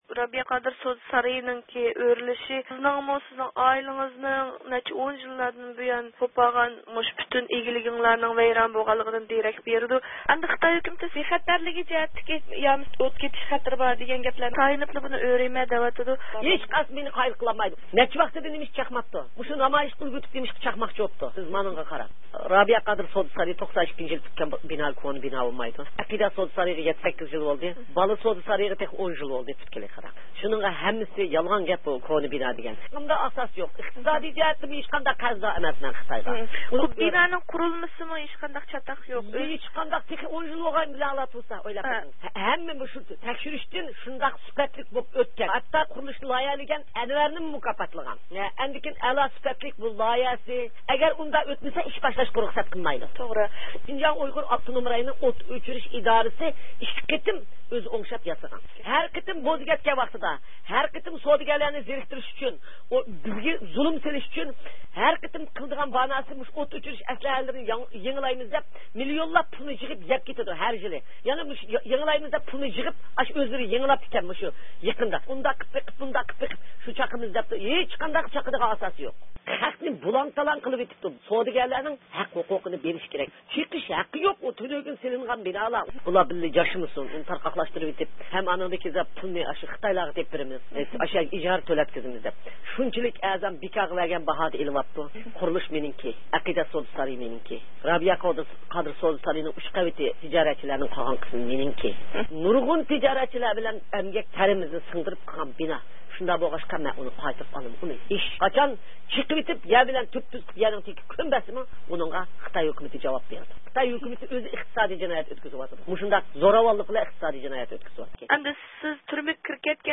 يۇقىرىدىكى ئاۋاز ئۇلىنىشىدىن، بۇ ھەقتە رابىيە قادىر خانىم بىلەن ئۆتكۈزگەن سۆھبىتىمىزنىڭ تەپسىلاتىنى ئاڭلايسىلەر.